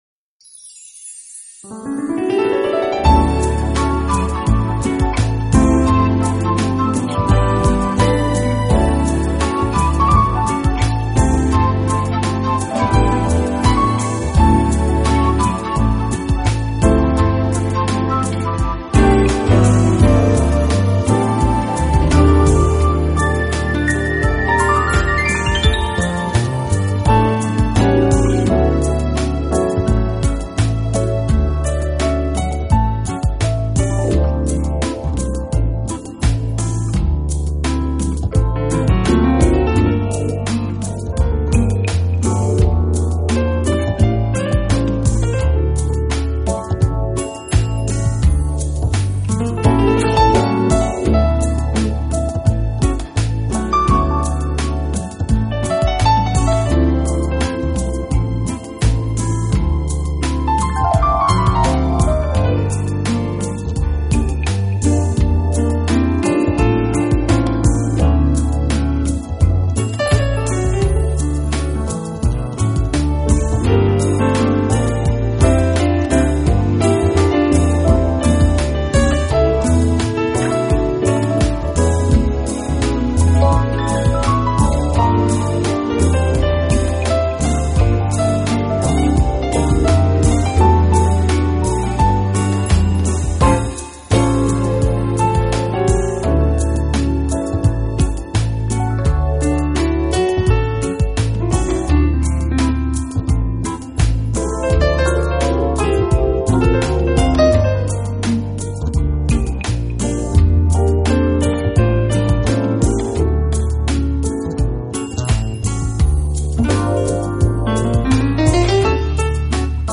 节奏明快，听了让心情愉悦